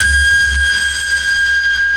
sonarPingWaterMediumShuttle1.ogg